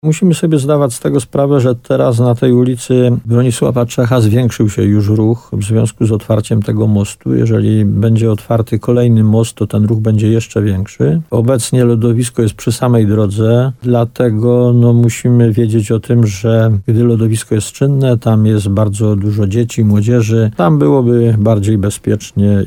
W nowej lokalizacji byłoby bardziej bezpiecznie – mówi burmistrz Władysław Bieda.